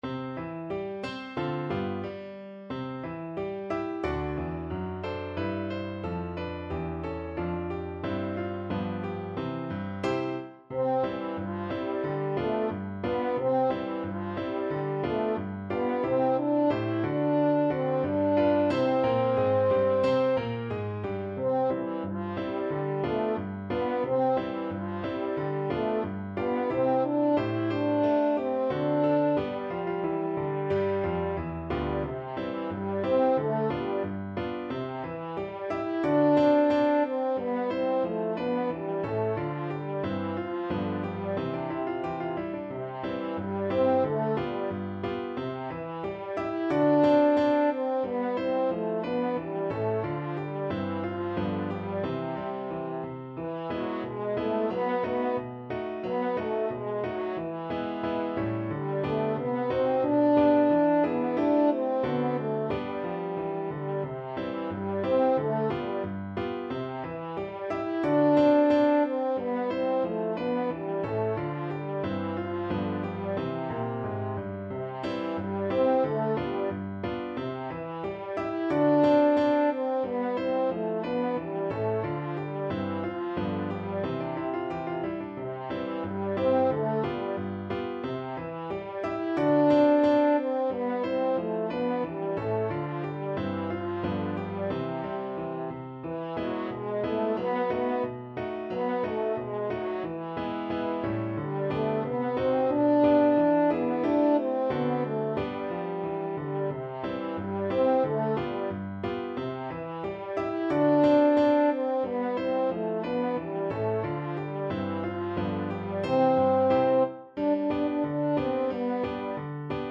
2/2 (View more 2/2 Music)
=90 Fast and cheerful
Pop (View more Pop French Horn Music)